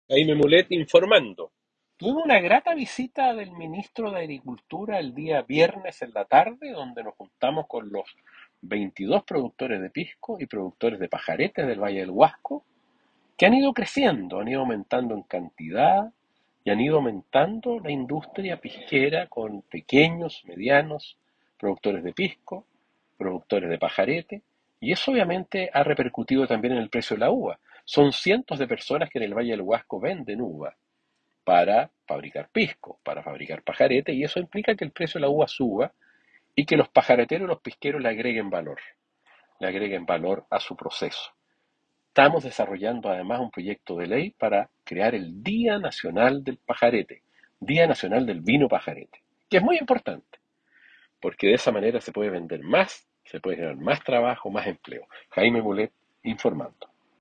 Audio Diputado Jaime Mulet sobre reunión que sostuvo con el ministro de Agricultura y productores de pisco y pajarete